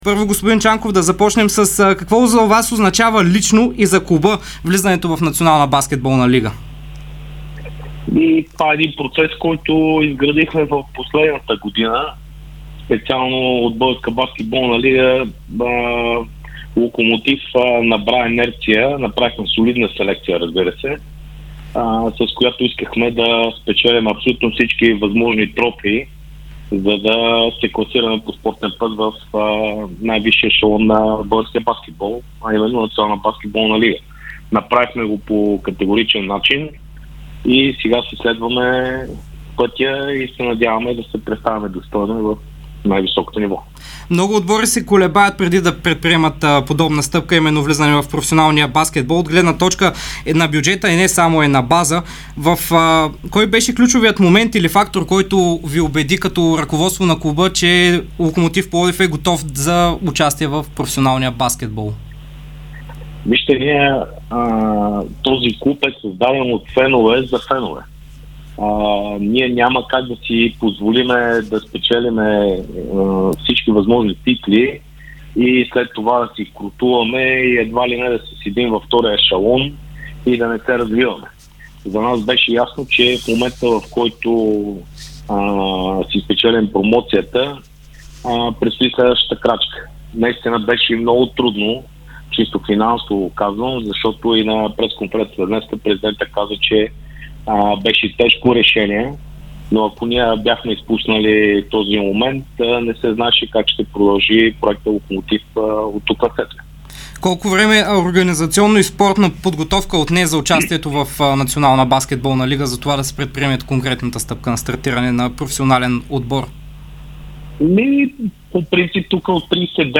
даде интервю за Дарик радио и Dsport. Той говори за процеса по влизане в Sesame НБЛ и заяви, че „смърфовете“ ще са фактор във висшия ешелон на българския баскетбол.